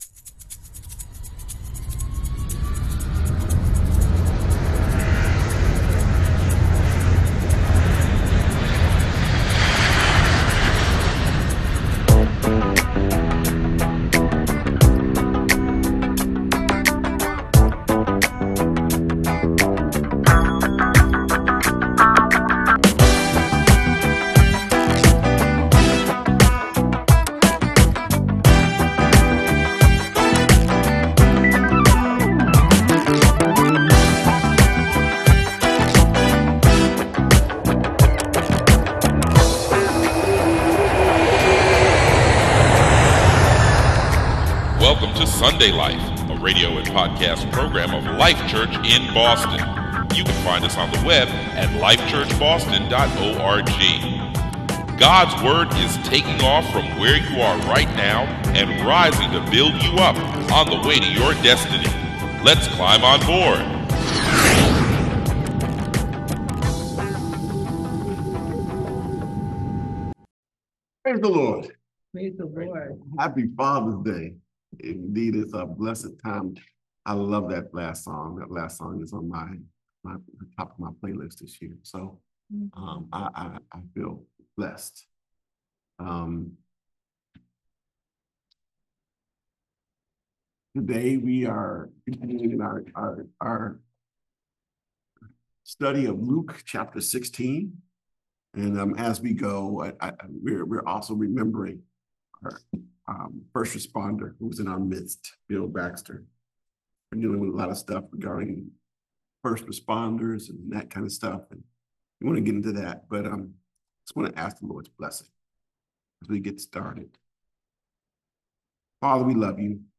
Notes for 06/15/2025 Sermon - Life Church